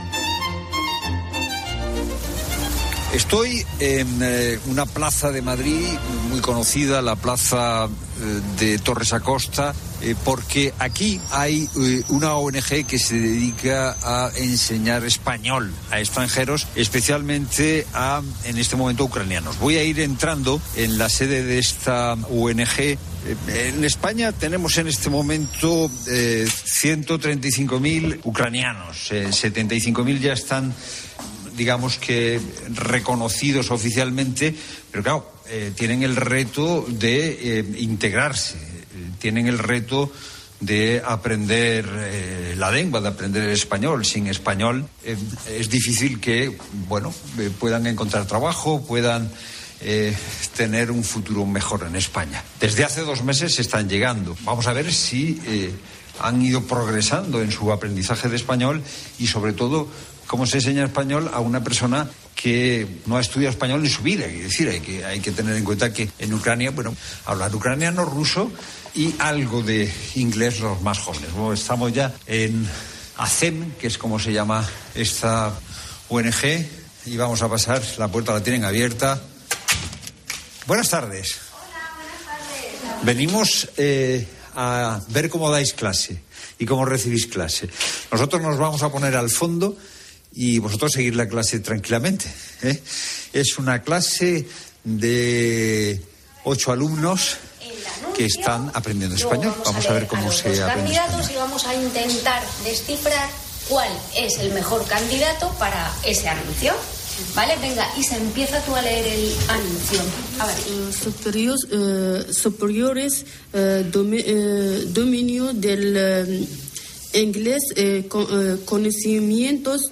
Se encuentran en una clase de 8 alumnos .
Puedes escuchar el reportaje completo aqui , en La Tarde de COPE